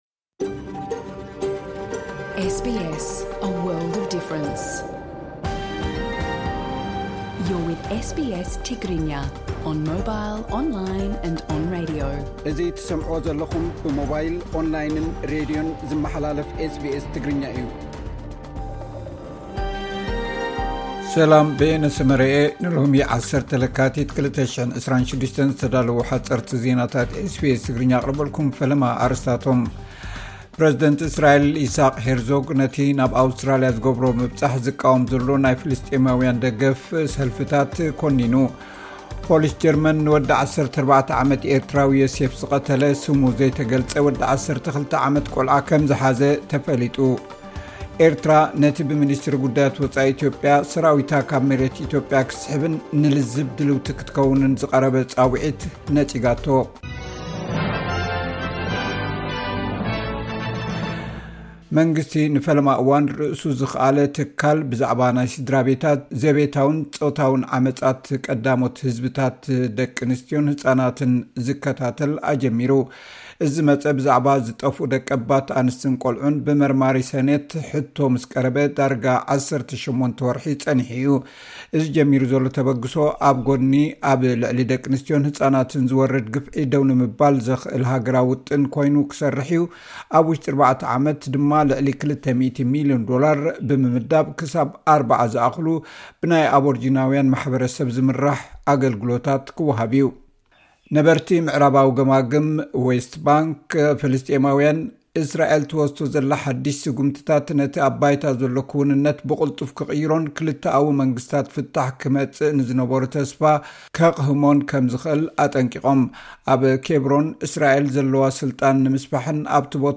ኤርትራ፡ ነቲ ብምኒስትሪ ጉዳያት ወጻኢ ኢትዮጵያ፡ ሰራዊታ ካብ መሬት ኢትዮጵያ ክትስሕብን ንልዝብ ድልውቲ ክትከውንን ዘቐረበ ጻውዒት ነጺጋቶ። (ሓጸርቲ ዜናታት )